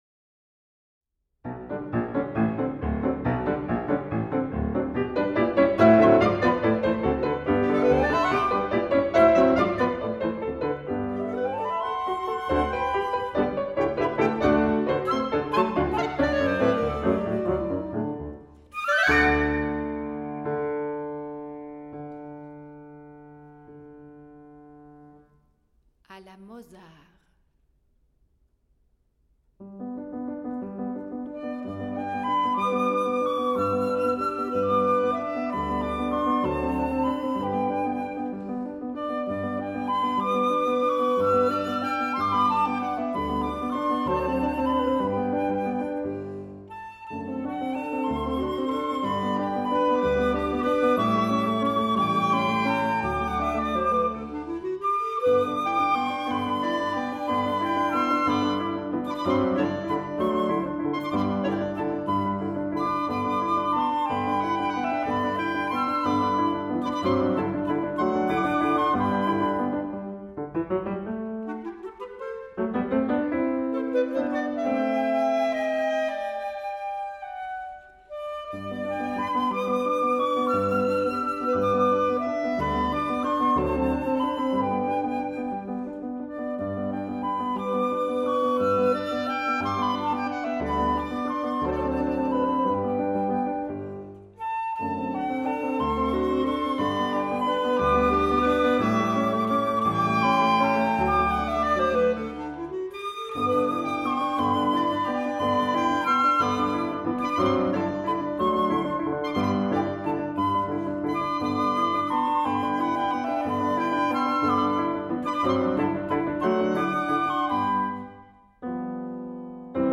Trio for Flute, Clarinet in C and Piano à la manière de (in the style of)Mozart, Verdi, Debussy, Chopin and J. Strauss